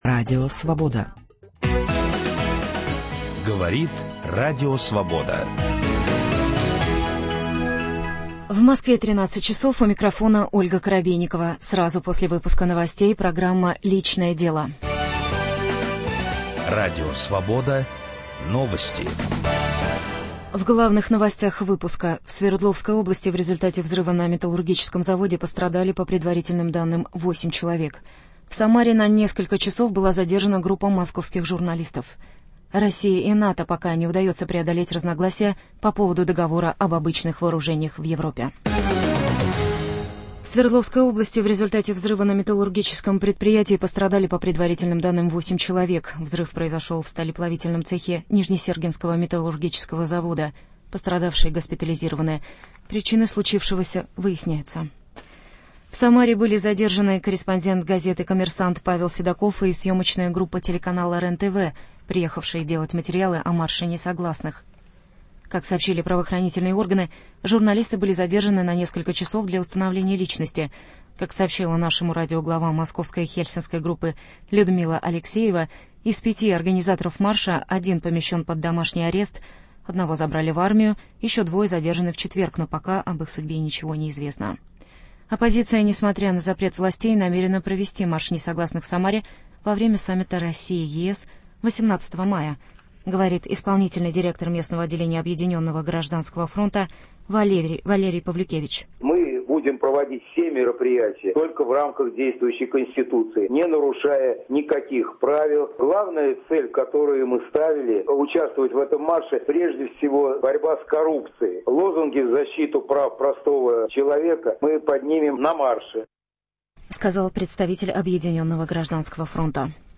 В прямом эфире мы будем говорить о том, легко ли дается нам принятие трудных решений.